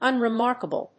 音節un・re・mark・a・ble 発音記号・読み方
/`ʌnrɪmάɚkəbl(米国英語), ʌnrɪˈmɑ:rkʌbʌl(英国英語)/